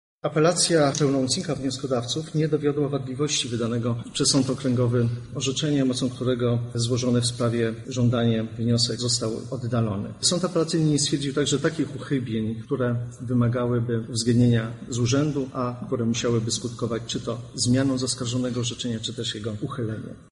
Wydany wyrok uzasadnia przewodniczący składu sędziowskiego sędzia Mariusz Młoczkowski.